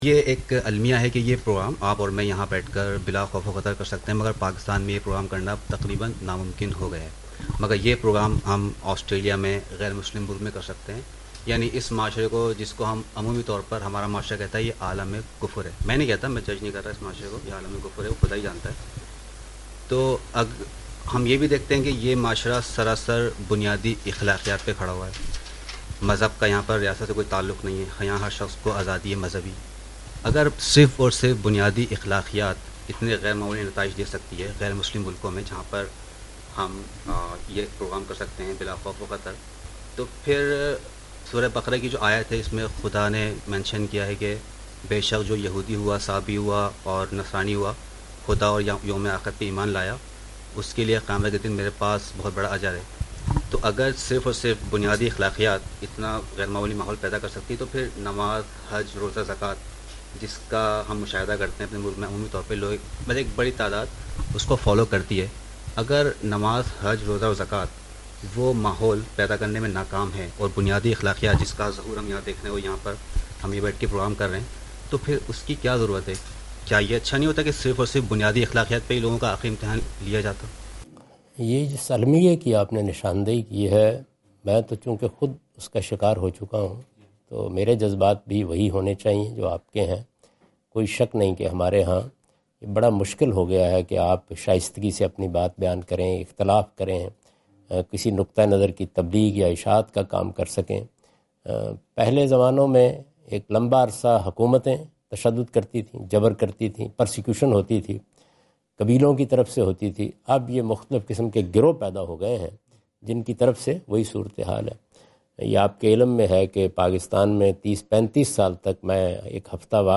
Javed Ahmad Ghamidi answer the question about "Importance of Moral Values and Rituals" during his Australia visit on 11th October 2015.
جاوید احمد غامدی اپنے دورہ آسٹریلیا کے دوران ایڈیلیڈ میں "اخلاقی اقدار اور عبادت کی اہمیت" سے متعلق ایک سوال کا جواب دے رہے ہیں۔